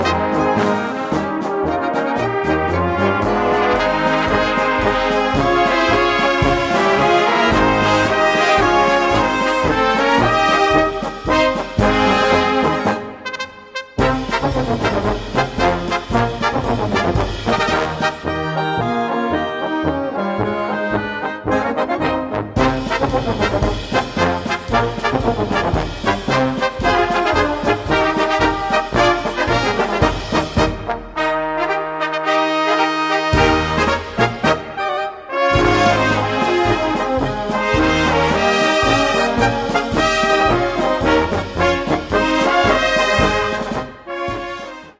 Gattung: Polka für Blasorchester
Besetzung: Blasorchester
für sinfonisches Blasorchester (mit Gesang) komponiert.